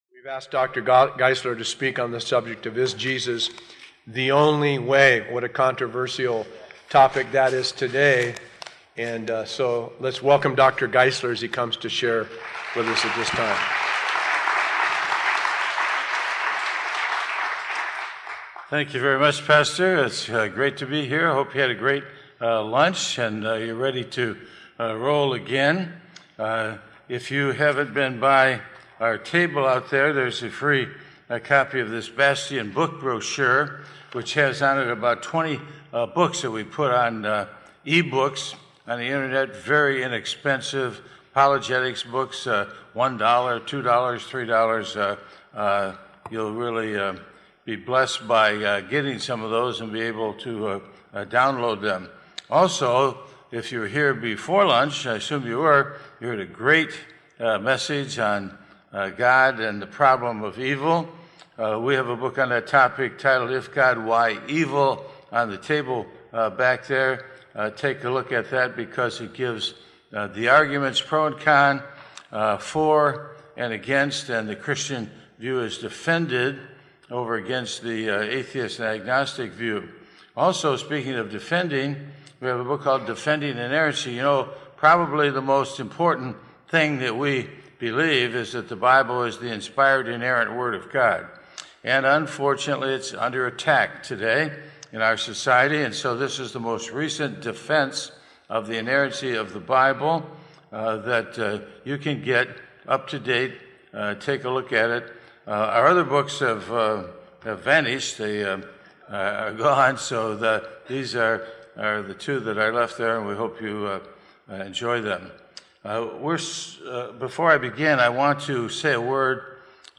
Dr. Norman Geisler teaches a session titled “Is Jesus The Only Way?”.